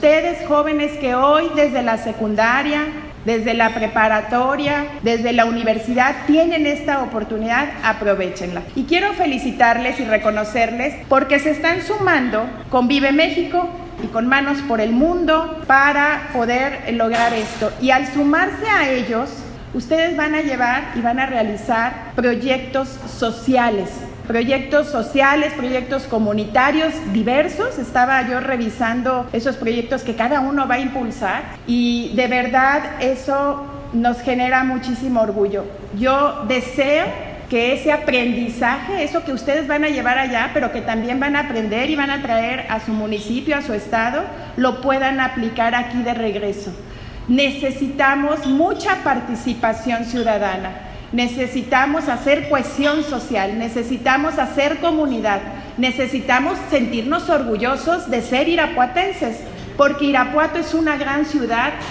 AudioBoletines
Lorena Alfaro García – Presidenta municipal